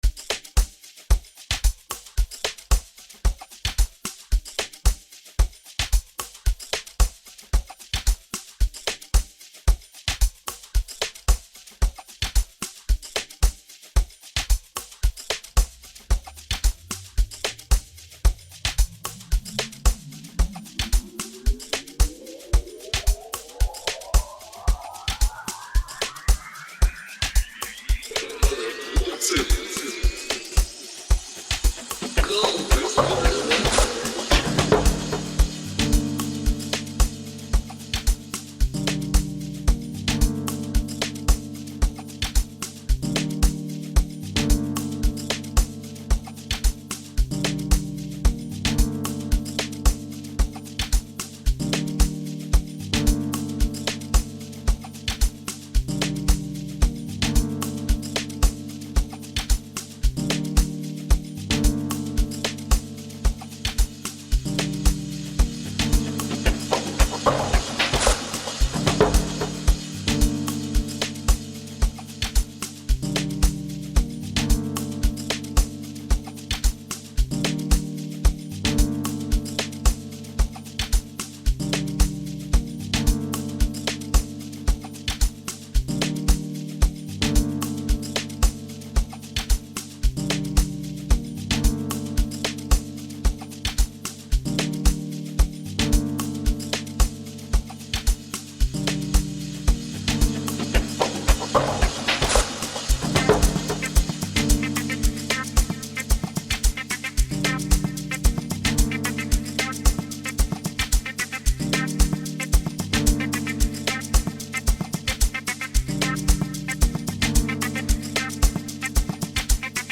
No matter the flavor of Amapiano you enjoy